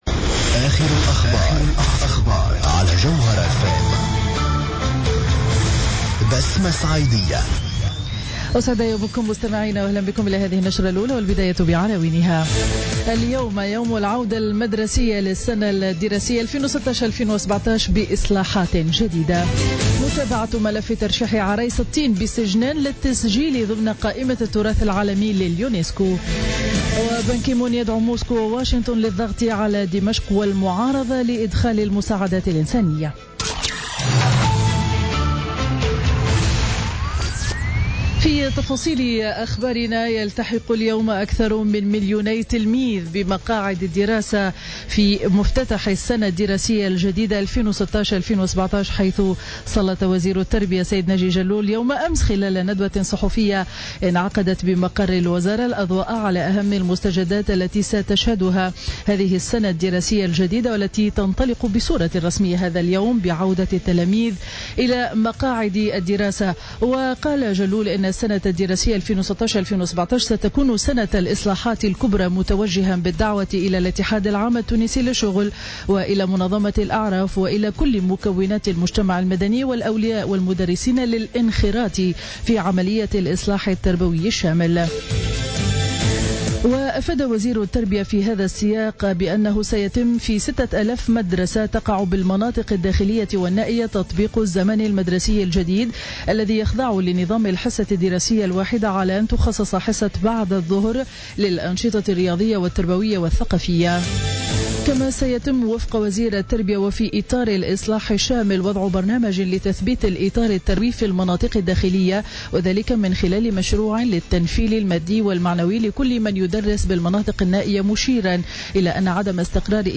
نشرة أخبار السابعة صباحا ليوم الخميس 15 سبتمبر 2016